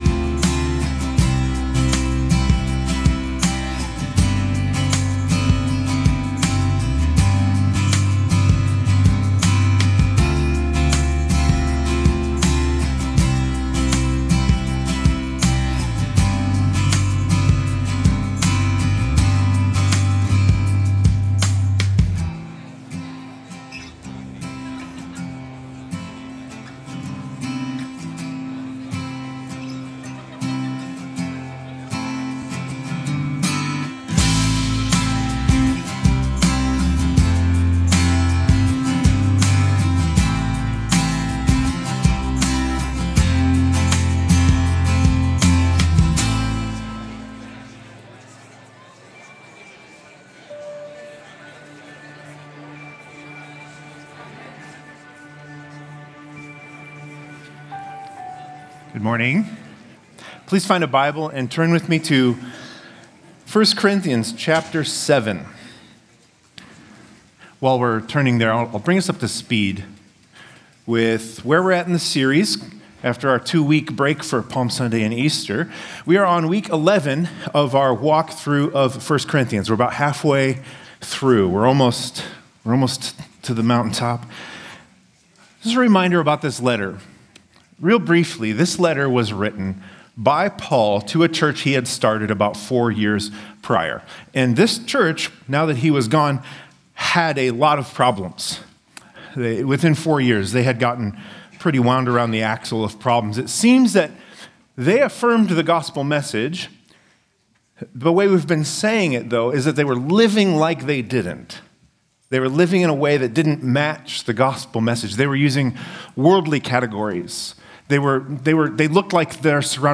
Stonebrook Sunday AM